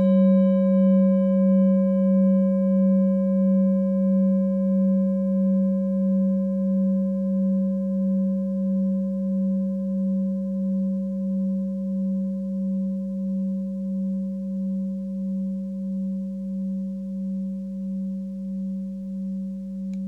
Klangschale Orissa Nr.15
Sie ist neu und wurde gezielt nach altem 7-Metalle-Rezept in Handarbeit gezogen und gehämmert.
(Ermittelt mit dem Filzklöppel oder Gummikernschlegel)
In unserer Tonleiter liegt dieser Ton nahe beim "Fis".
klangschale-orissa-15.wav